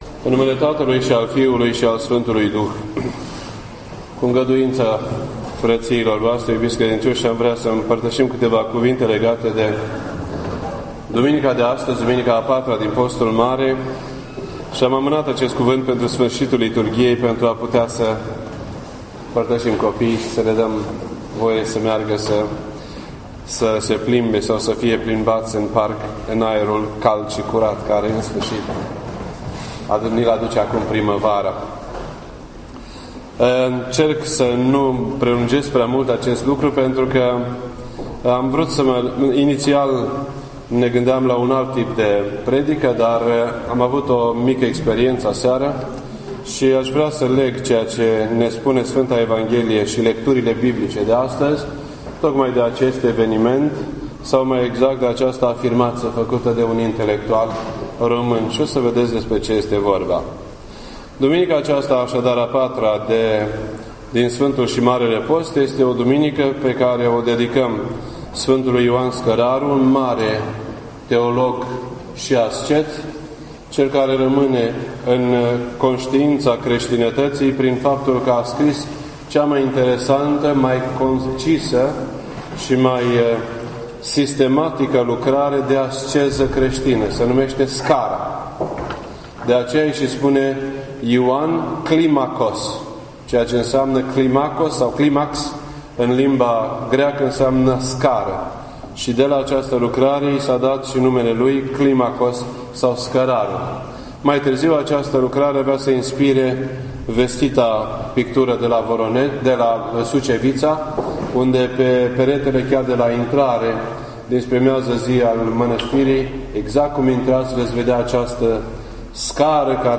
This entry was posted on Thursday, April 4th, 2013 at 8:38 PM and is filed under Predici ortodoxe in format audio.